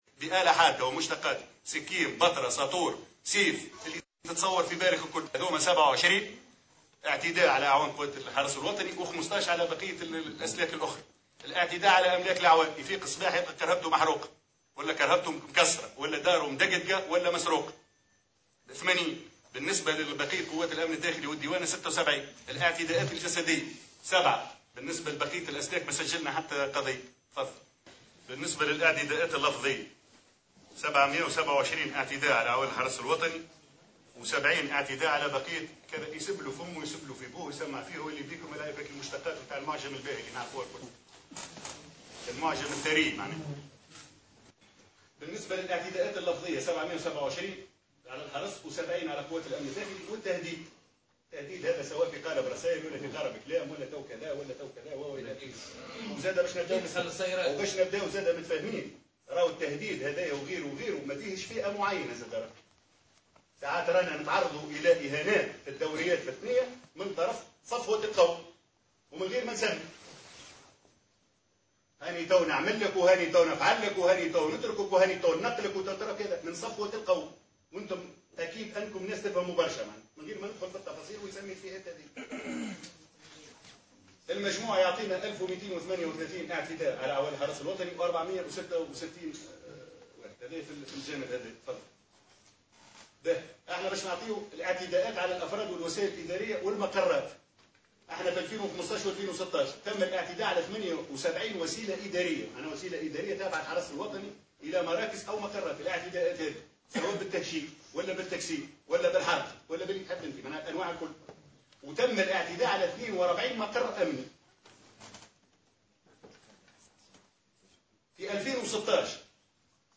ندوة صحفية عُقدت صباح اليوم الجمعة بمقرّ وزارة الداخلية